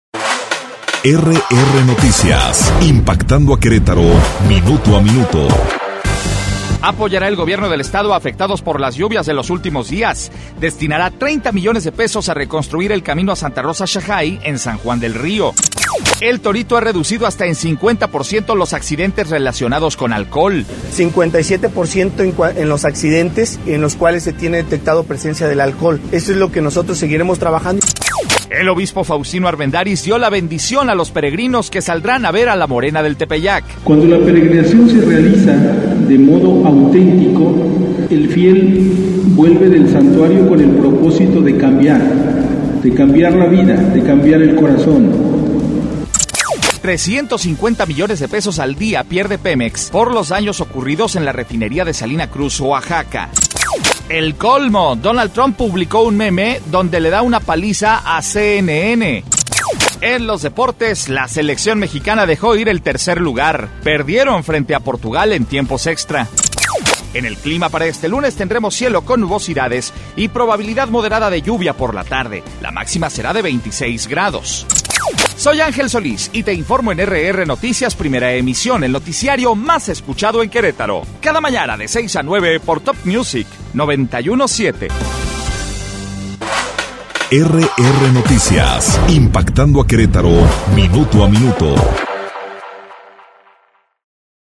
Resumen Informativo 03 de julio - RR Noticias